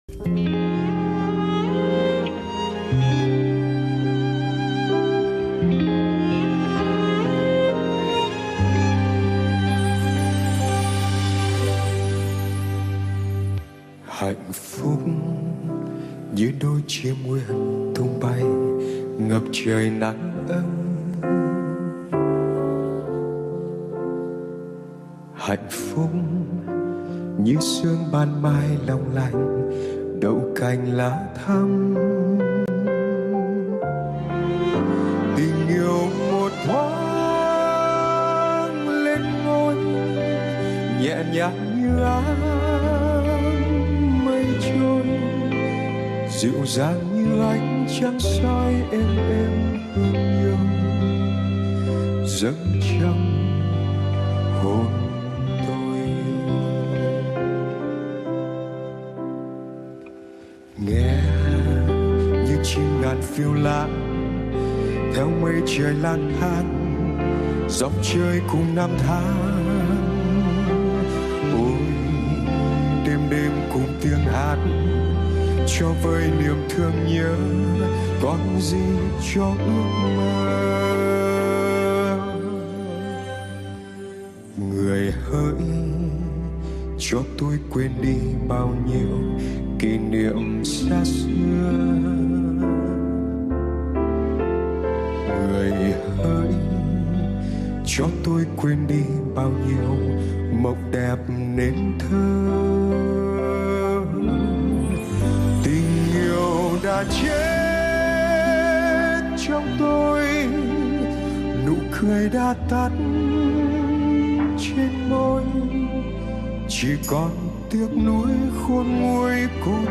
Ca sĩ trẻ Lân Nhã đã định hình phong cách biểu diễn của mình qua những sáng tác nổi tiếng trước 75 lẫn những bản nhạc ngoại quốc lời Việt cùng với phần hoà âm tươi mới mang lại nét đầy đặn và duyên dáng.